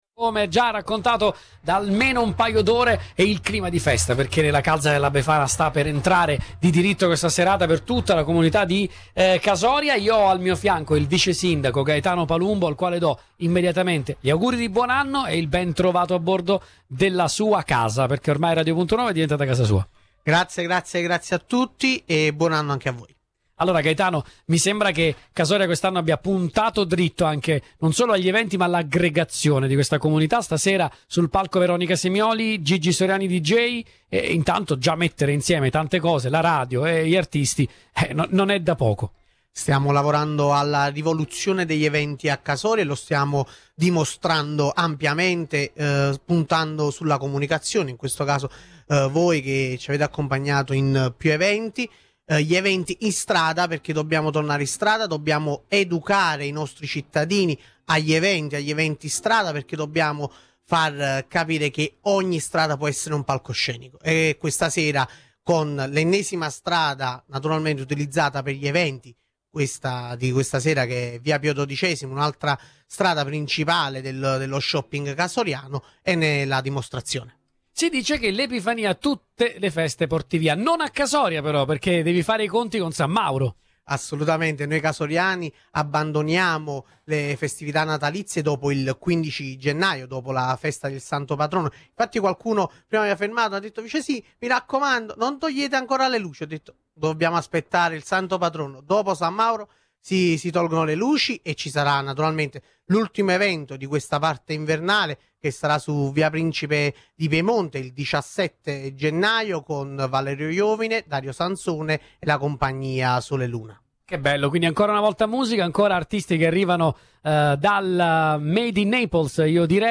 Sotto le stelle di una serata baciata dal bel tempo, Radio Punto Nuovo ha raccontato in diretta ogni istante di un evento che ha trasformato il cuore dello shopping in un’arena di festa, tra le bolle di sapone per i più piccoli, le eccellenze gastronomiche locali (come i dolci omaggi della Boulangerie 2.0) e le grandi performance live.